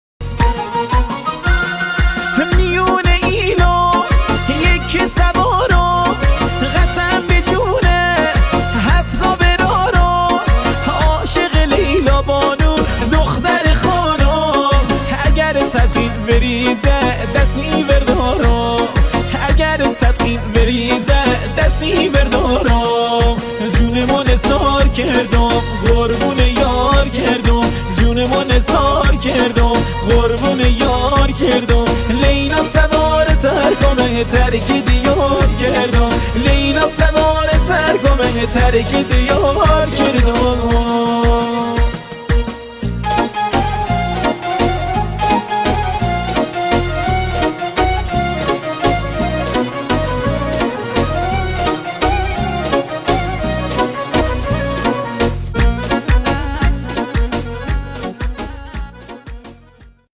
لری شیرازی